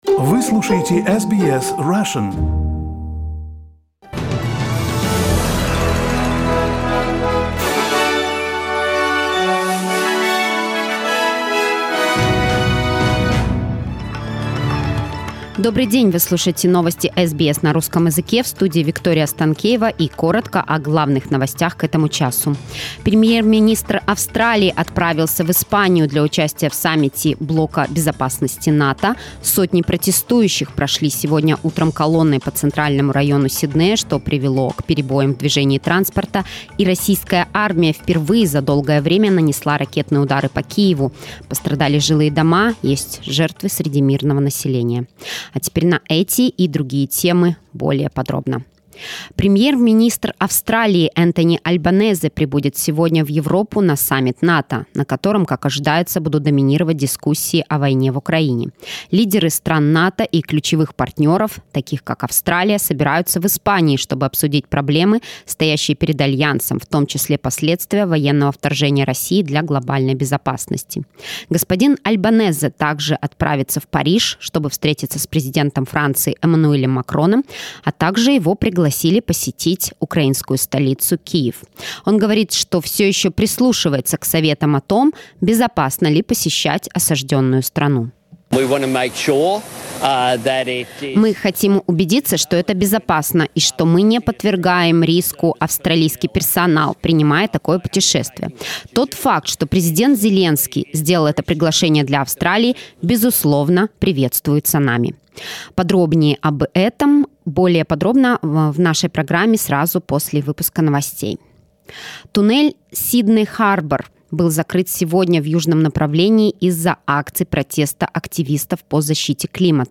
SBS news in Russian — 27.06.22